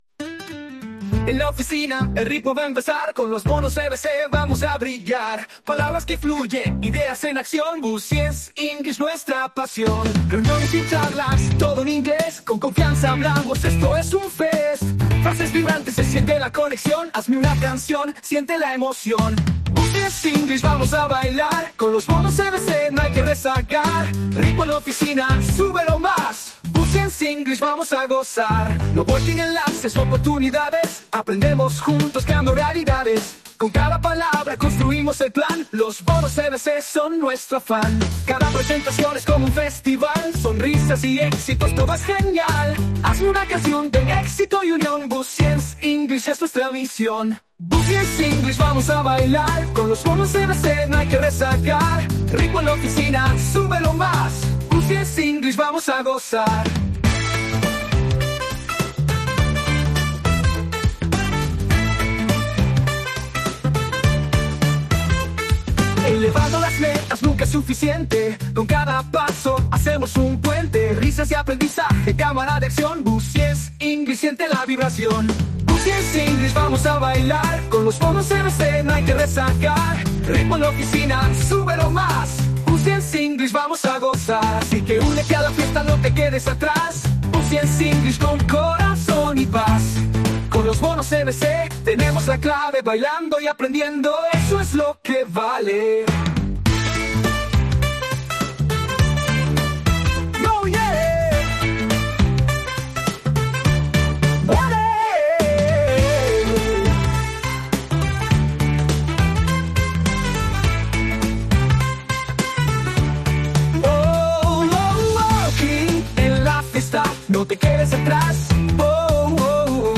¿Estás listo para subir el ritmo en la oficina?
por Los EBCs